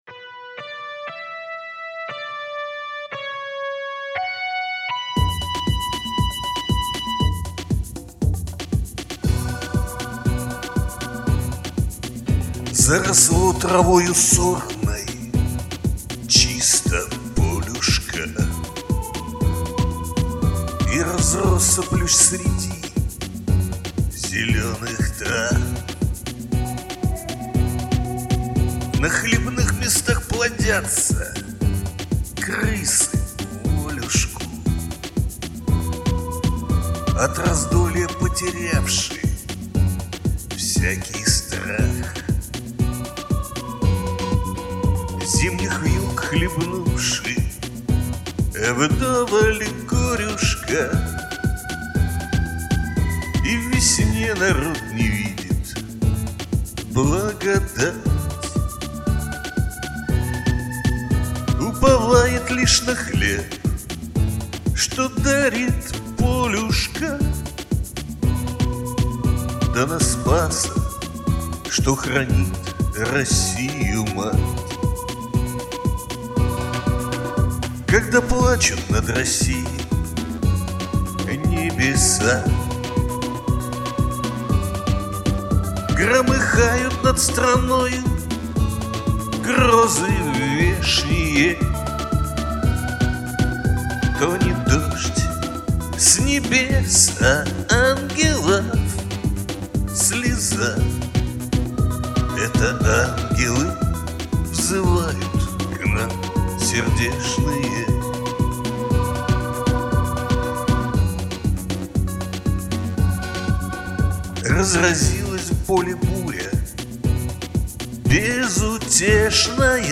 Жанр: русская патриотическая